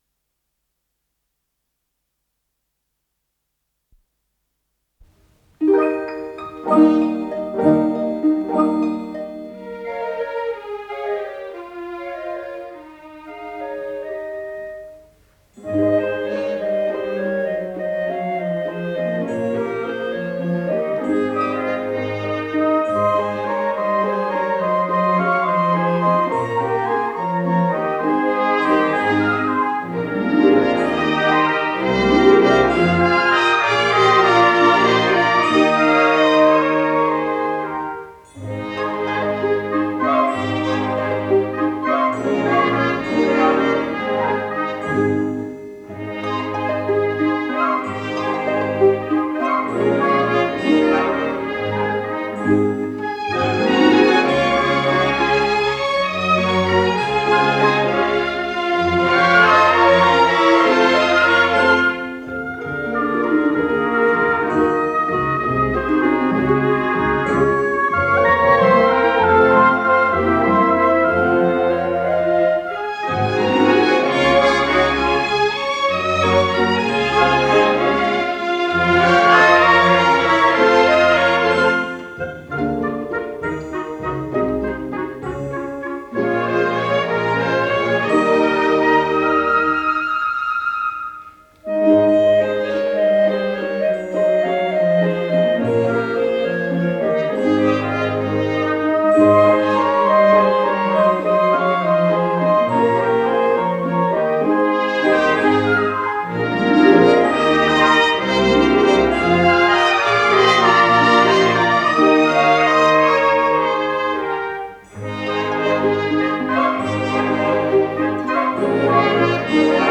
с профессиональной магнитной ленты
ПодзаголовокЗаставка